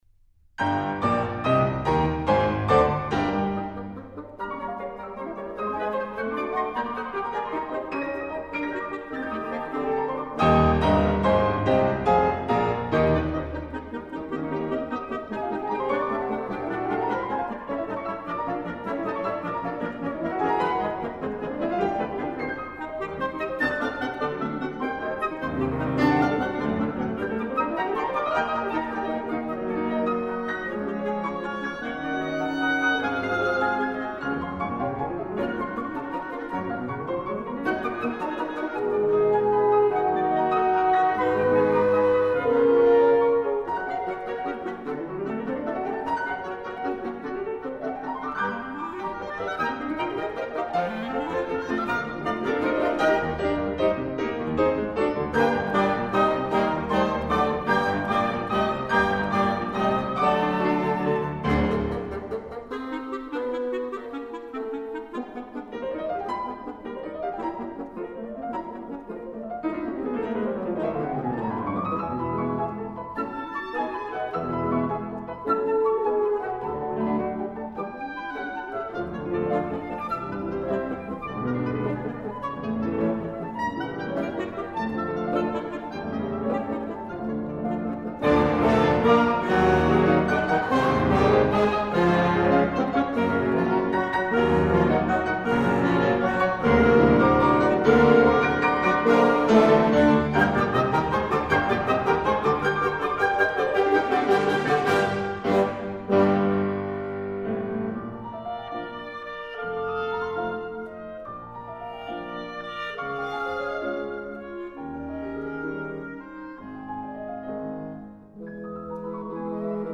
Imaginary Folk Music. Located in Altdorf, at the north gate of the Gotthard Pass, Alpentöne has become over the past decade a renowned biannual festival of imaginary folk music featuring Europe's alpine music cultures.